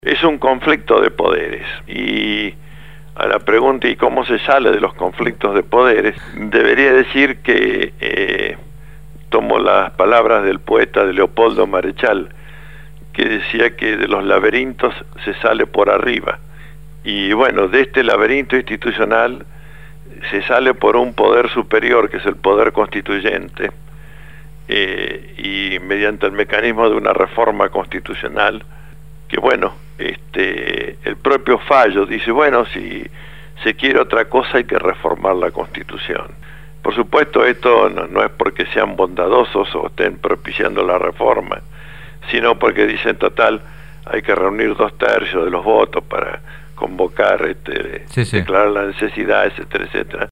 En diálogo telefónico